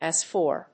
アクセントàs for…